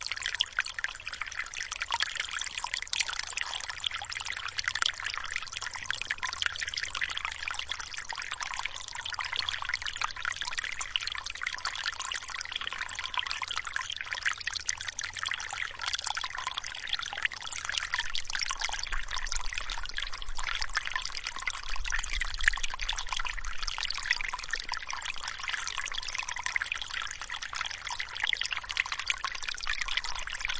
描述：我在2005年夏天去了日本的克塔河。这个声音是当时录制的。
标签： 日本 现场记录 自然 河流
声道立体声